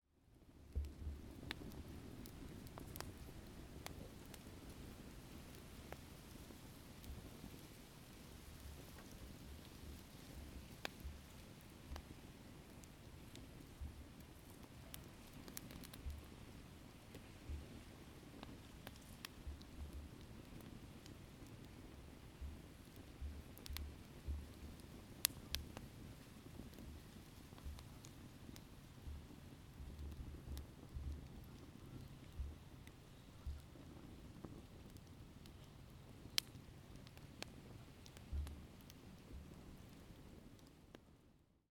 10/01/2024 18:00 Les ombres s’étirent sur le sable au pied des touffes d’herbe à chameaux, le soleil se couche derrière la dune. Le petit bois crépite, l’odeur de fumée se répand dans l’air.